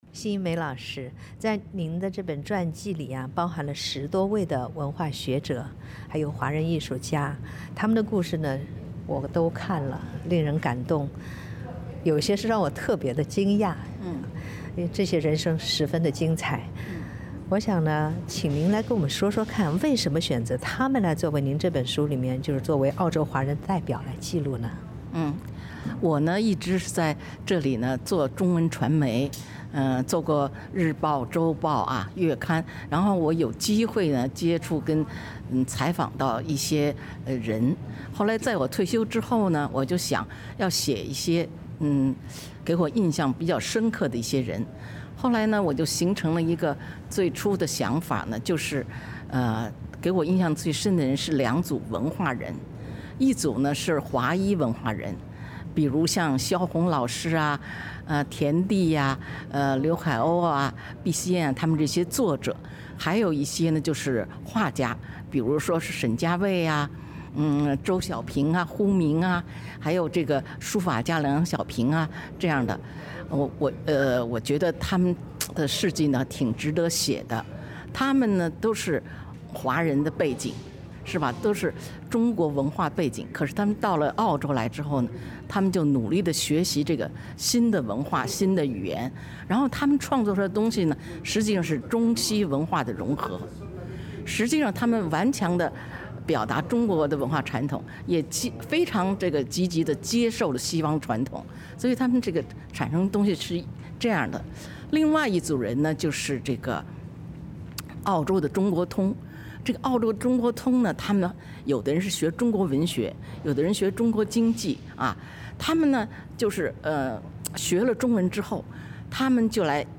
在采访中